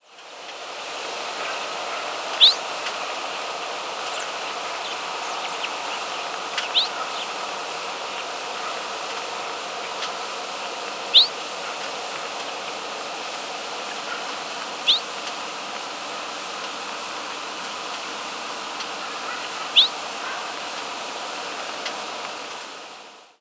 Western Meadowlark diurnal
Western Meadowlark diurnal flight calls
Small flock including one Eastern Meadowlark flight call.